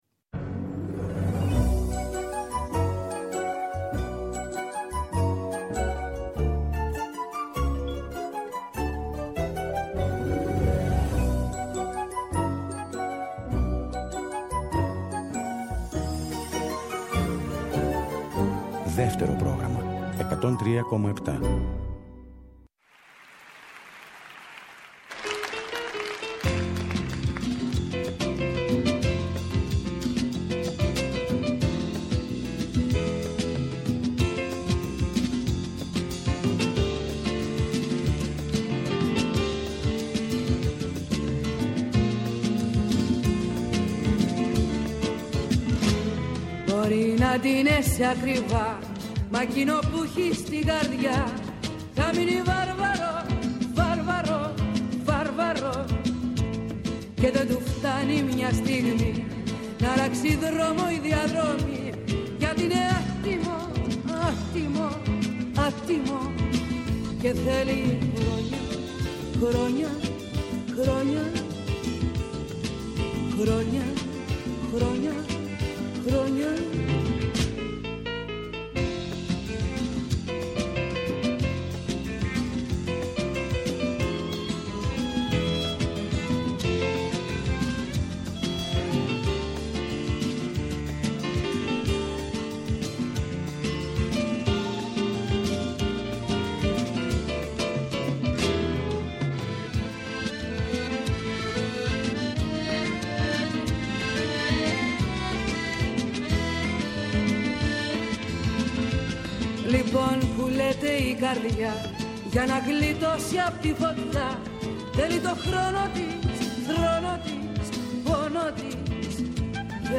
Στο “Μελωδικό Αντίδοτο” oι καινούριες μουσικές κάνουν παρέα με τις παλιές αγαπημένες σε μια ώρα ξεκούρασης καθώς επιστρέφουμε από μια κουραστική μέρα.